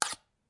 刀片振动和故障" Shot Knive2
描述：记录的刀片声音。
Tag: 刀片声 单击 打击乐器 录音 毛刺 叶片 振动 现场录音 拍摄 声音